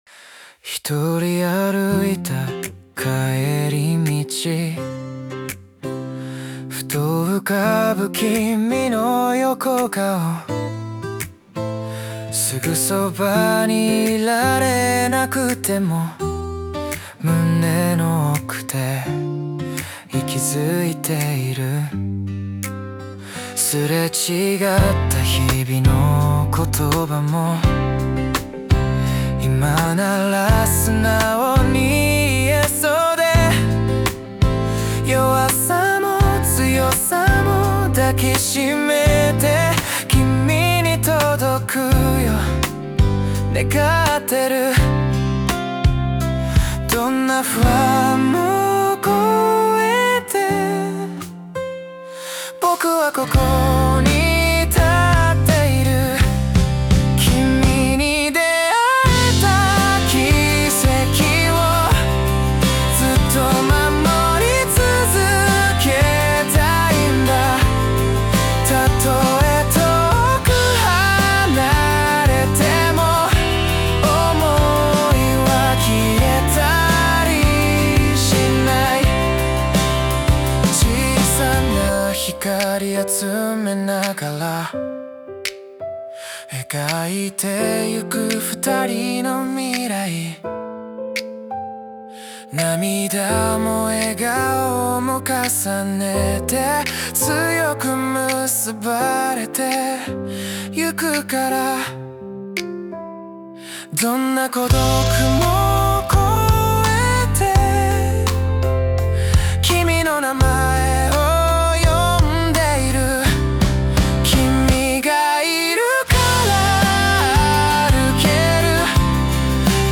著作権フリーオリジナルBGMです。
男性ボーカル（邦楽・日本語）曲です。
王道の少し切ないラブバラードです♪♪